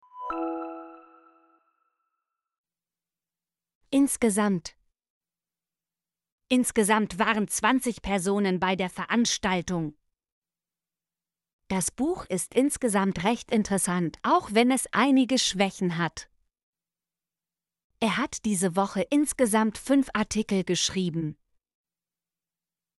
insgesamt - Example Sentences & Pronunciation, German Frequency List